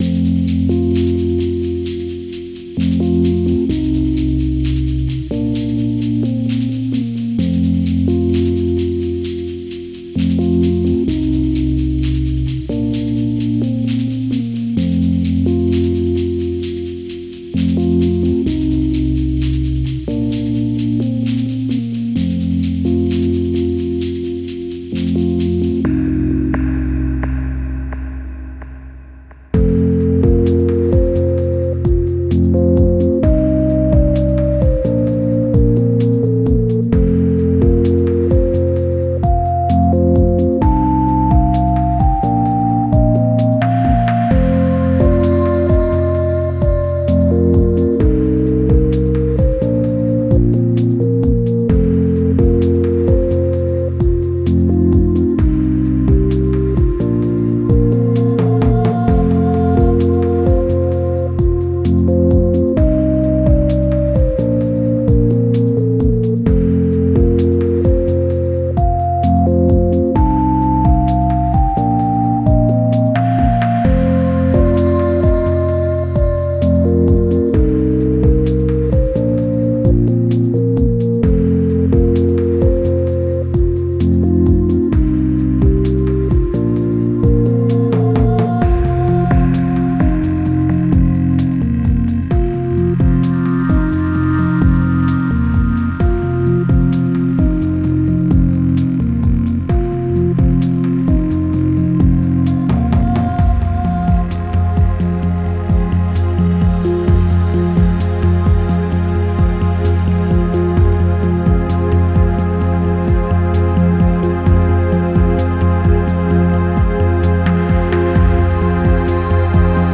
Genre: Ambient